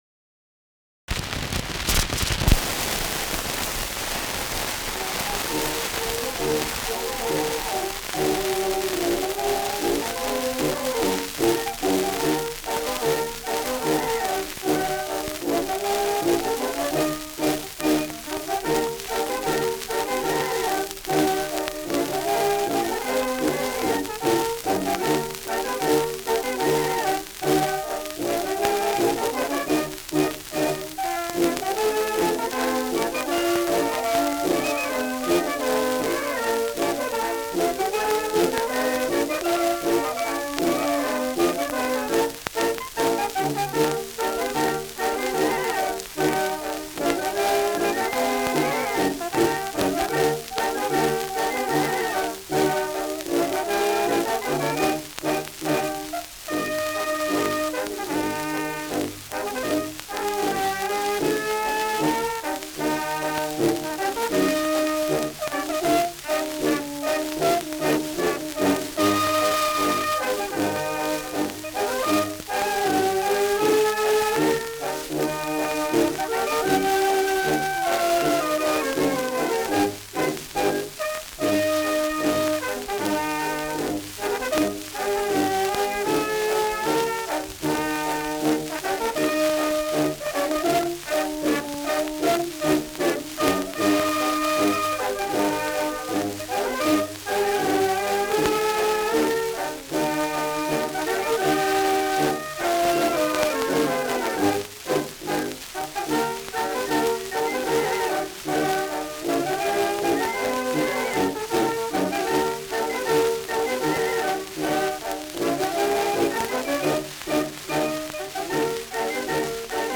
Schellackplatte
Starkes Grundrauschen : Verzerrt an lauteren Stellen : Vereinzelt stärkeres Knacken